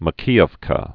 (mə-kēəv-kə, mä-kēyēw-kä)